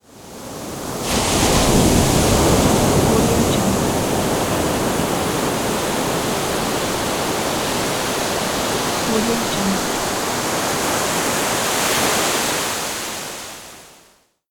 دانلود افکت صدای برخورد موج اقیانوس به ساحل دریا
Sample rate 16-Bit Stereo, 44.1 kHz
Looped No